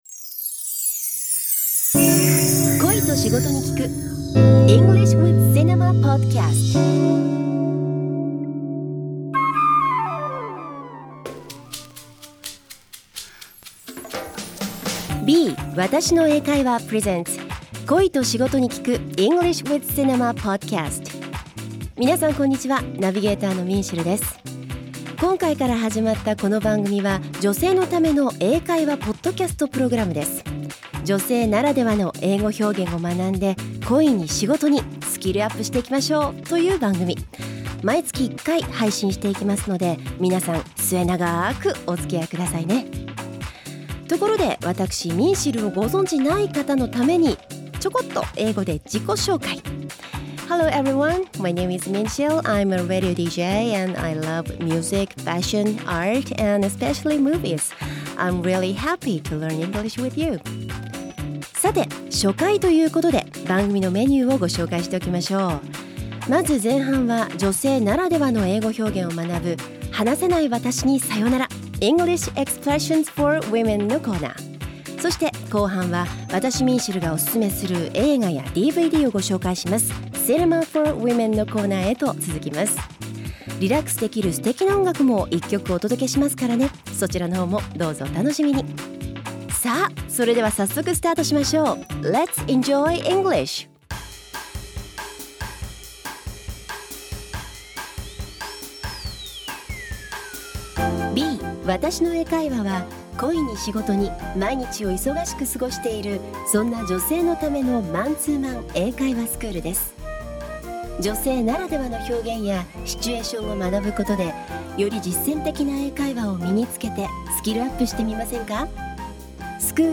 ふと、以前bがJ-Waveさんと共同企画で行った番組収録を行った時に